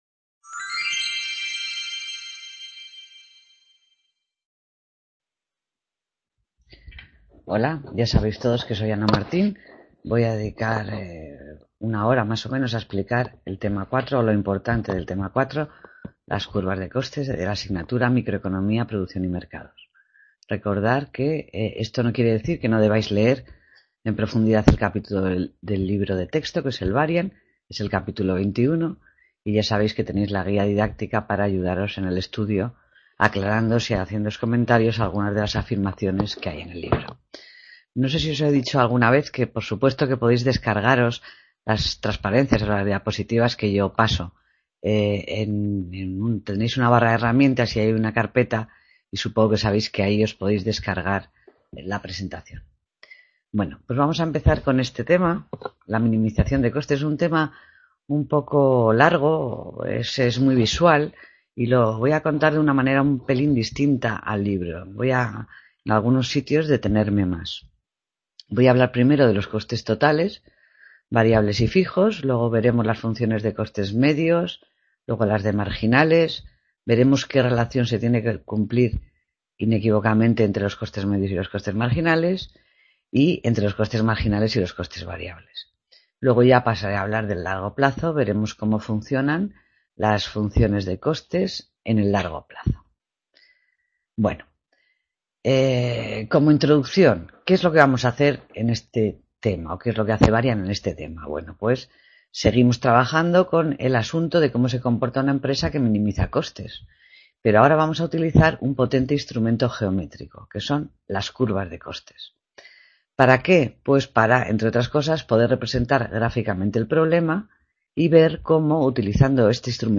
Clase Tema 4: Las curvas de costes | Repositorio Digital